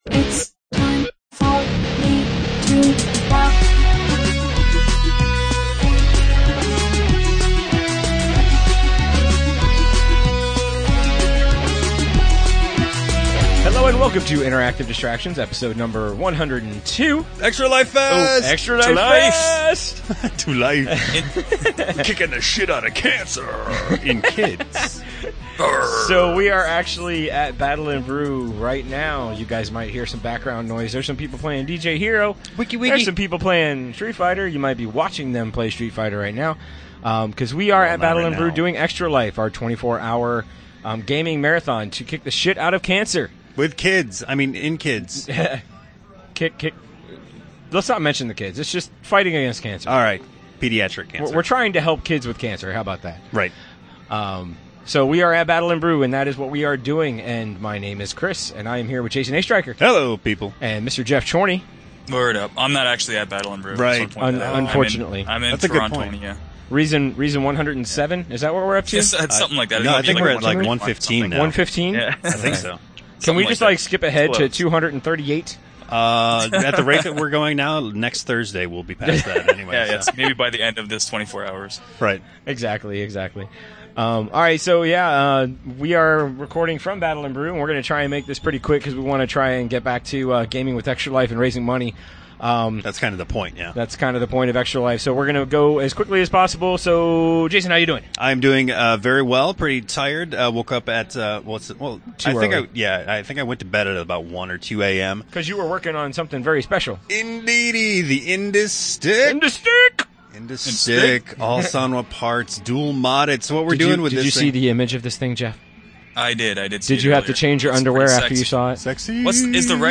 Well we’re a little late again, but hopefully you will excuse us since we did record during the weekend of Extra Life! Which we talk about to start off the show.